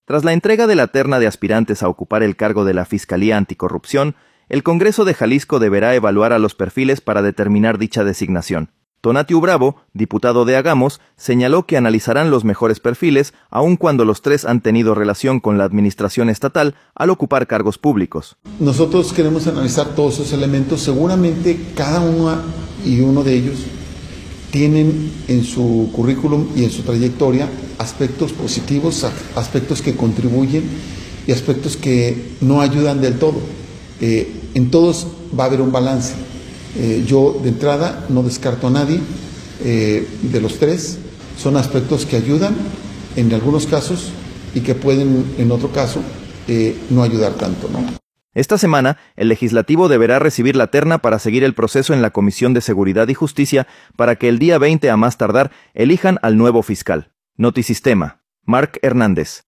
Tras la entrega de la terna de aspirantes a ocupar el cargo de la Fiscalía Anticorrupción, el Congreso de Jalisco deberá evaluar a los perfiles para determinar dicha designación. Tonatiuh Bravo, diputado de Hagamos, señaló que analizarán los mejores perfiles aun cuando los tres han tenido relación con la administración estatal al ocupar cargos públicos.